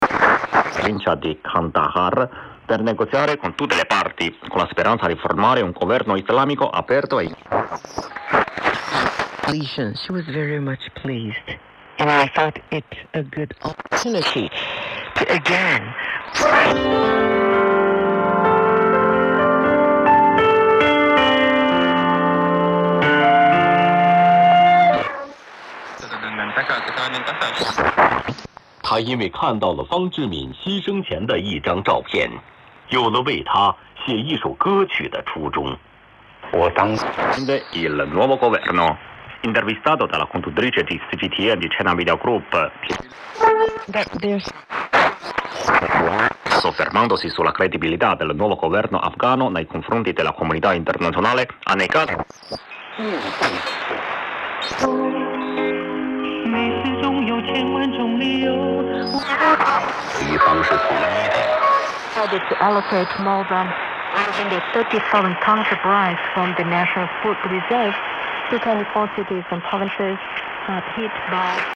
Первая запись АМ сигналов.
AM7mHz.mp3